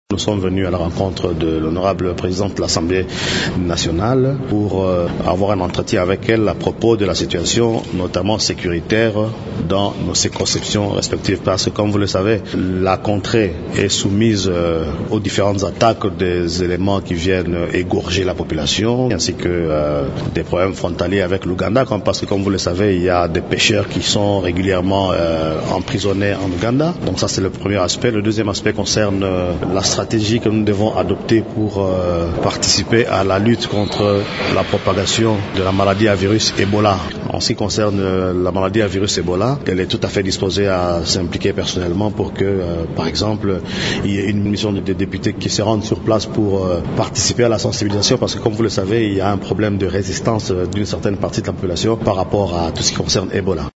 « En ce qui concerne la maladie à virus Ebola, la présidente d l’Assemblée nationale est disposée à s’impliquer personnellement pour qu’il y ait une mission des députés qui vont se rendre sur place pour participer à la sensibilisation parce qu’il y a un problème de résistance d’une certaine partie de la population, par rapport à tout ce qui concerne Ebola », a rapporté le député national Grégoire Kiro.